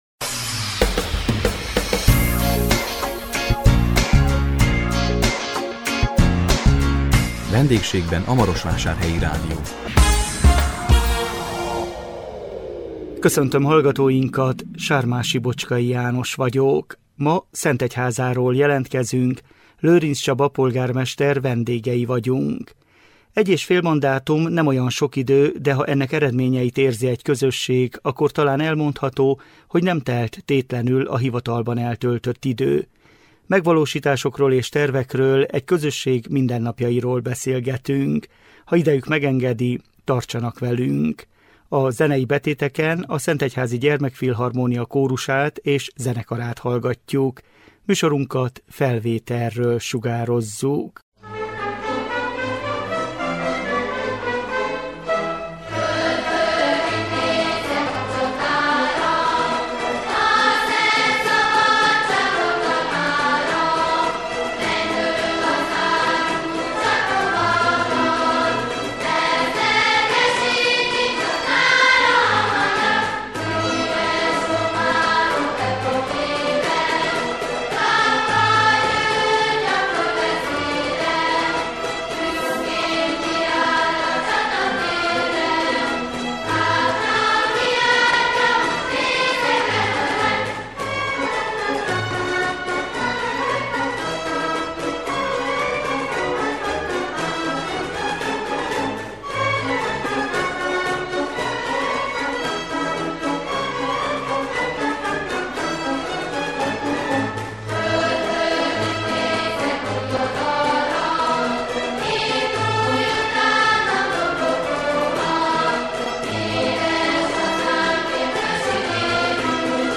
A 2026 április 2-án közvetített VENDÉGSÉGBEN A MAROSVÁSÁRHELYI RÁDIÓ című műsorunkkal Szentegyházáról jelentkezünk, Lőrincz Csaba polgármester vendégei voltunk.